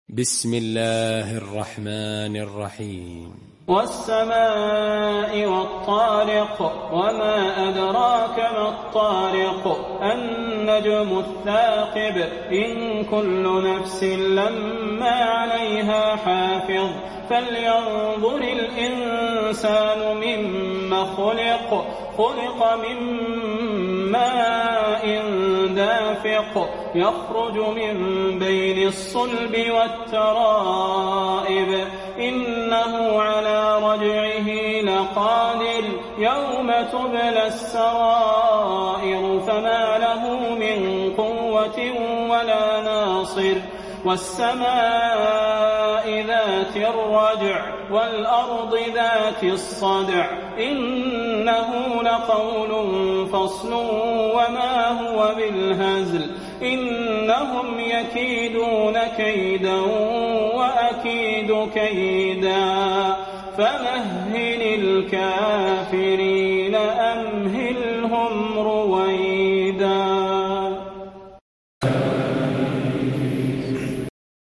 المكان: المسجد النبوي الفاتحة